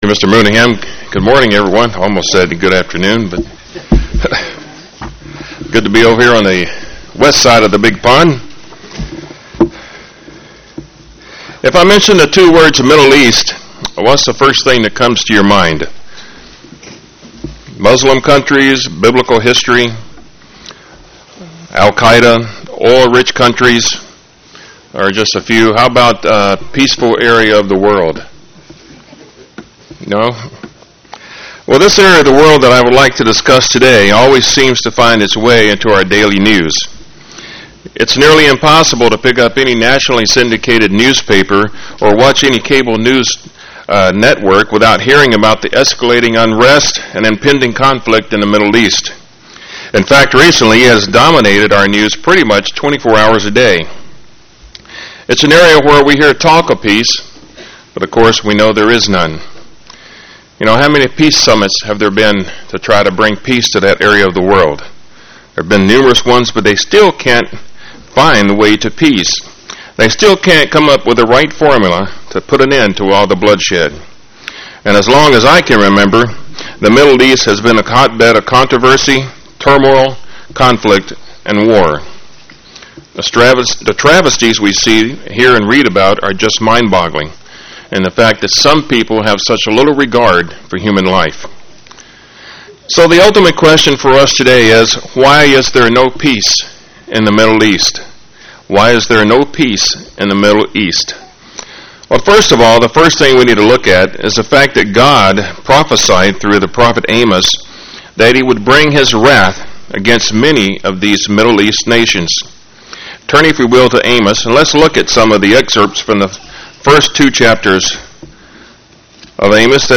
Print The areas of why there is no peace in the Middle East UCG Sermon Studying the bible?